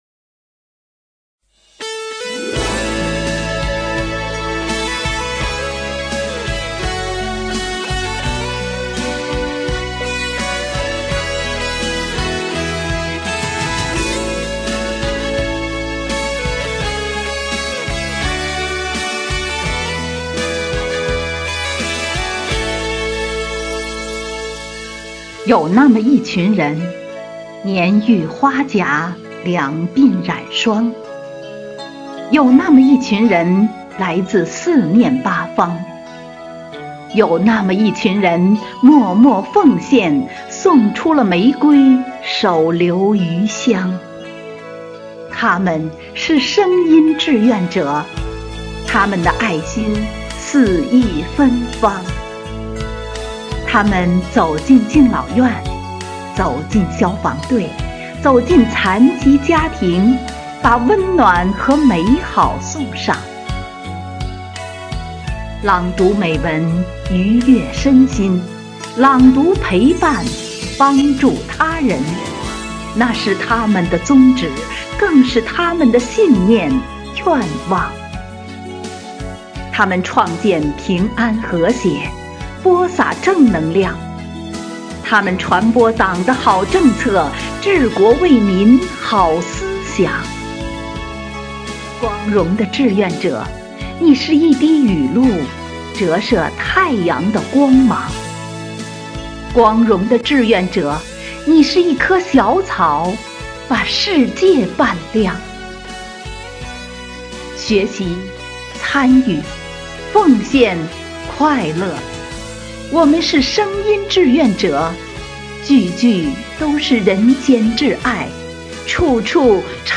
暨中华诗韵支队第13场幸福志愿者朗诵会